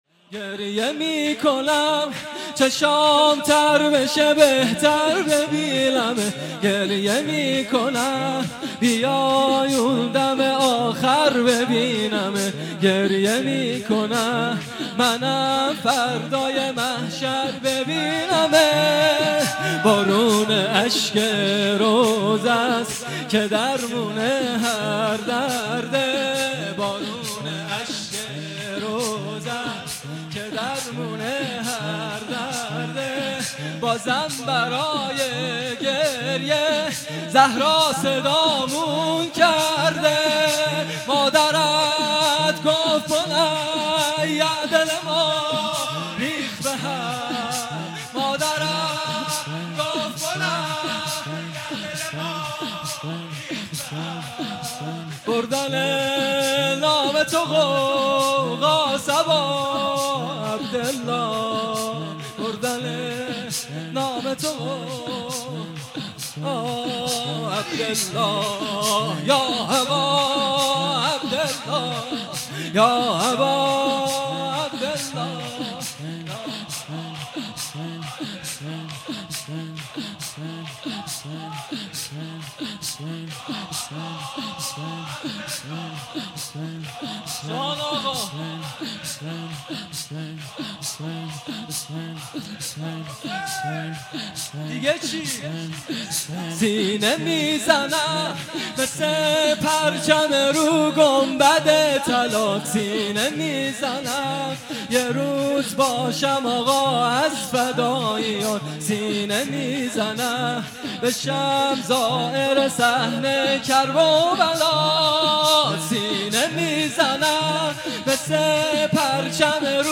شور | گریه میکنم چشمام تر بشه بهتر ببینمت
مداحی
شب هشتم محرم 1441 | مسجد ملا اسماعیل